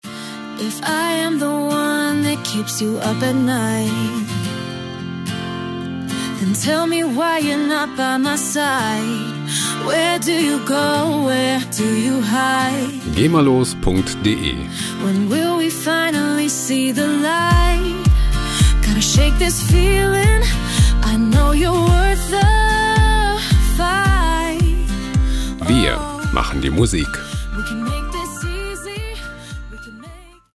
Ballads Loops gemafrei
Musikstil: Indie Ballad
Tempo: 110 bpm